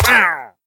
Minecraft Version Minecraft Version 1.21.5 Latest Release | Latest Snapshot 1.21.5 / assets / minecraft / sounds / mob / wandering_trader / death.ogg Compare With Compare With Latest Release | Latest Snapshot
death.ogg